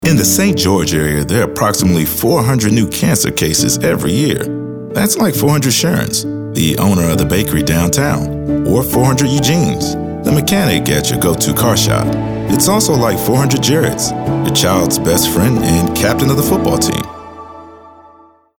His dynamic range and caring tone make him a compelling choice for any brand looking to connect with its audience.
African American, announcer, Booming, caring, compelling, concerned, confident, conversational, Deep Voice, friendly, genuine, Gravitas, homespun, informative, inspirational, Matter of Fact, Medical, mellow, middle-age, midlife, motivational, narrative, professional, real, sincere, smooth, storyteller, thoughtful, warm